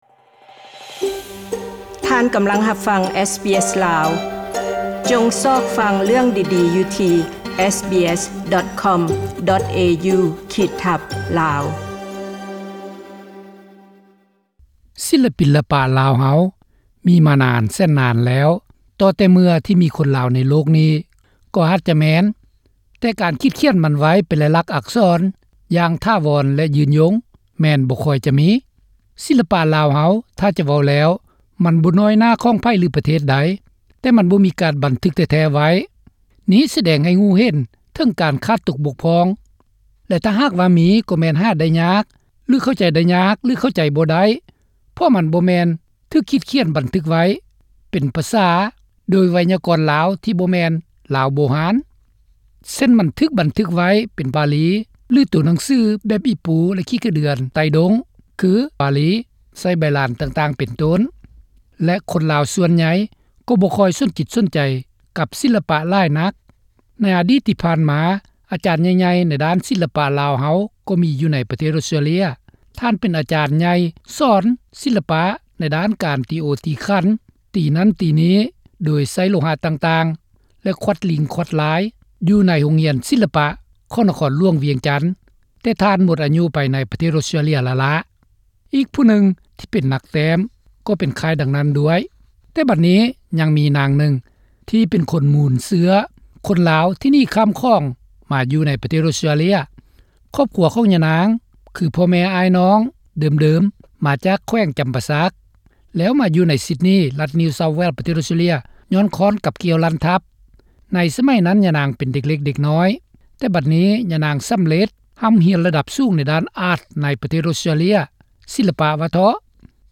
ສ ຳ ພ າ ດ : ຄົນອອສເຕຼລຽນລາວສົນໃຈໃນສິນລະປະລາວຢ່າງລຶກຊຶ້ງ